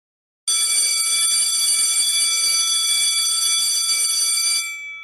School Bell Ringing